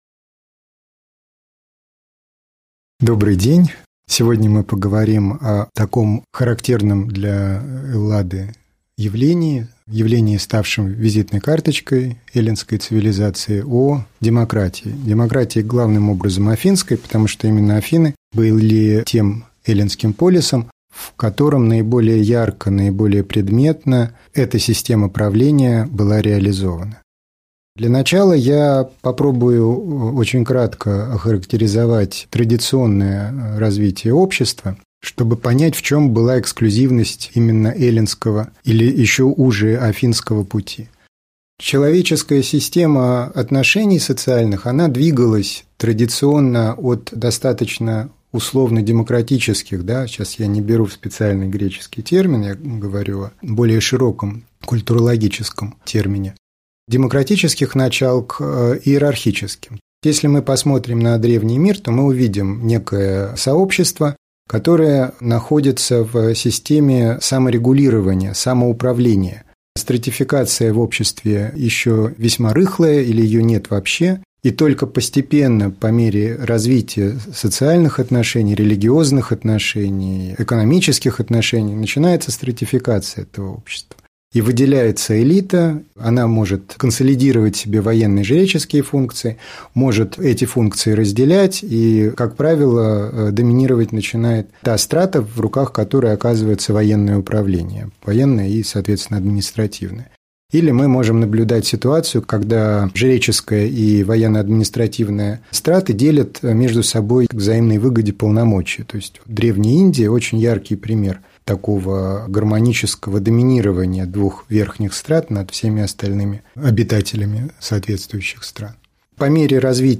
Аудиокнига Лекция «Демократия» | Библиотека аудиокниг